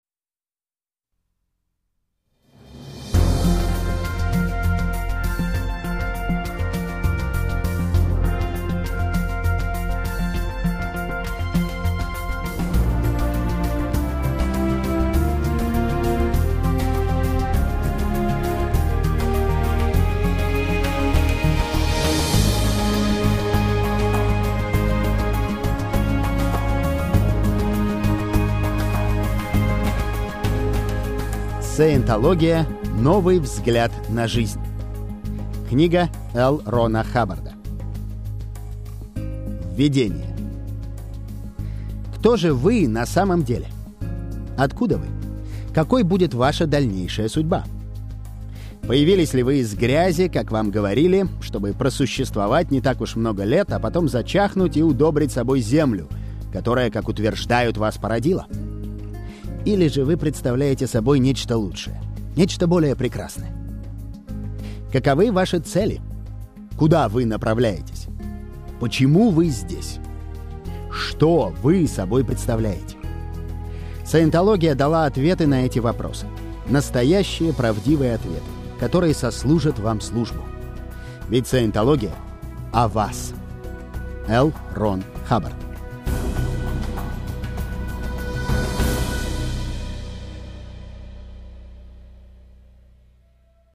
Аудио-книга: